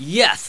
Amiga 8-bit Sampled Voice
1 channel
bh_yes!.mp3